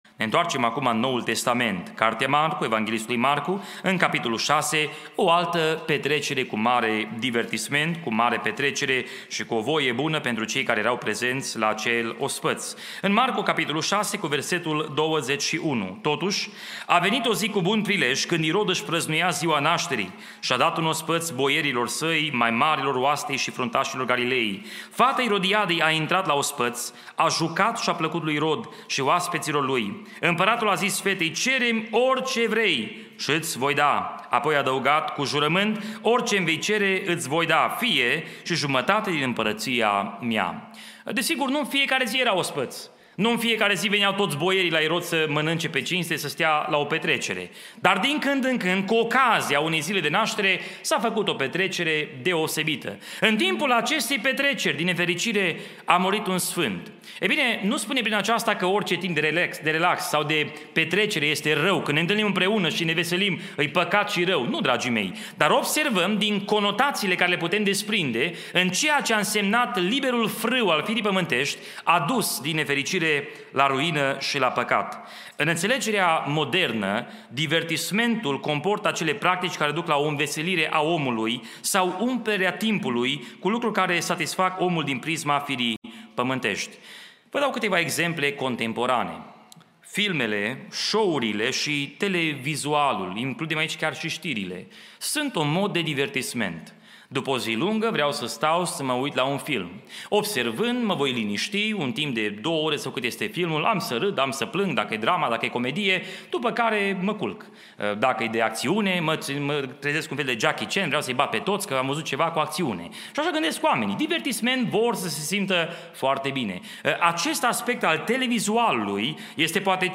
Cuprinde o selectie de predici audio si text care te ajuta sa intelegi de unde vii, cine esti si ce vrea Dumnezeu de la tine.